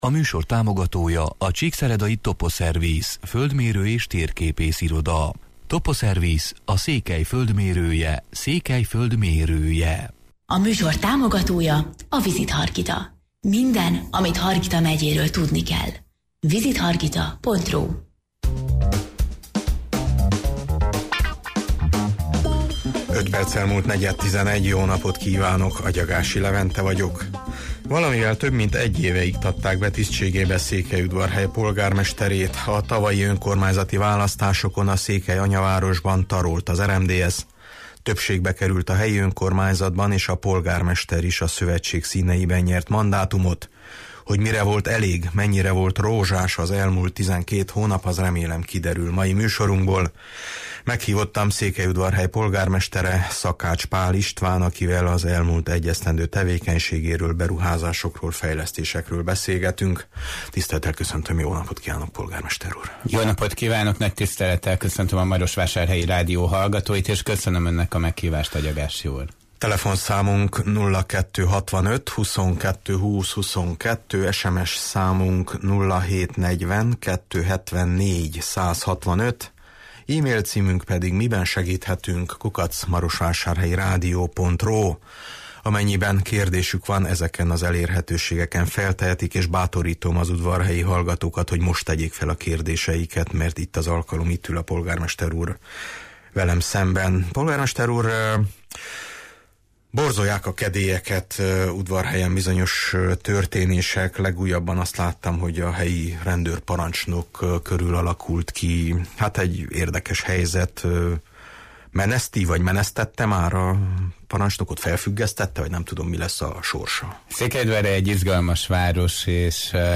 Meghívottam Székelyudvarhely polgármestere, Szakács-Paál István, akivel az elmúlt 1 esztendő tevékenységéről, beruházásokról, fejlesztésekről beszélgetünk: